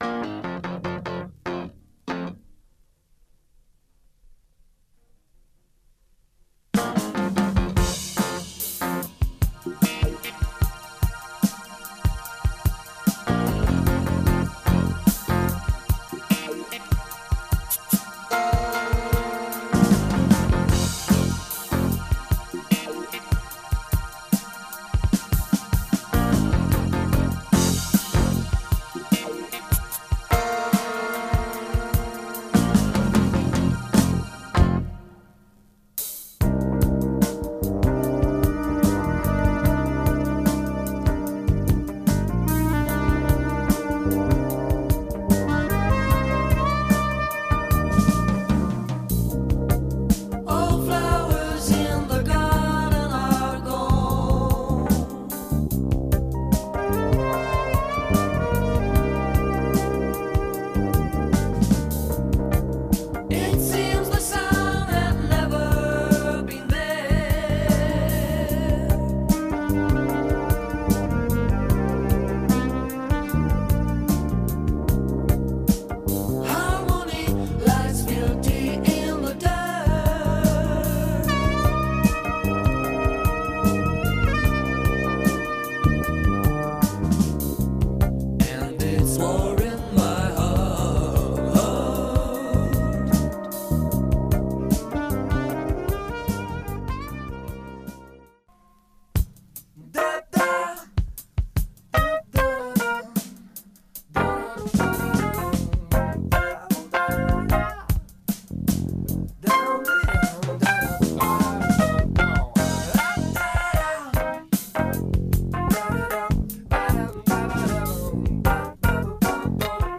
with sensual groove, but there are killer beats here too
Deep, very deep funky vibes here, really.